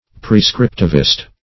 \pre*scrip"tiv*ist\
prescriptivist.mp3